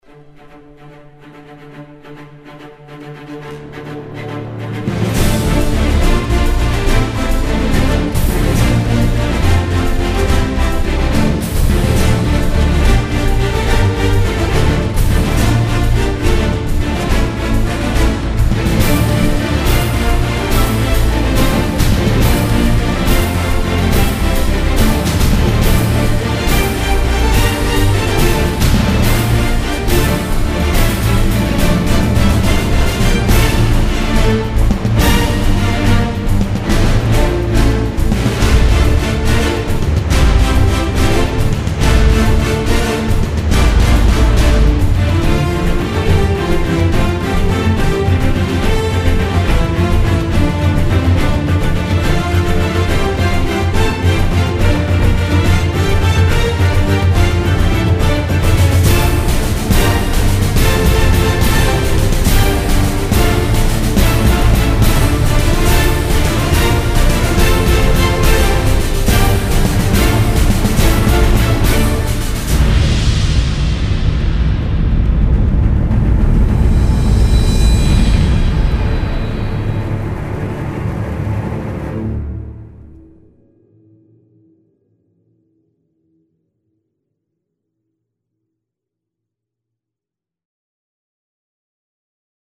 آلبومی مشهور،بسیار زیبا و با انرژی.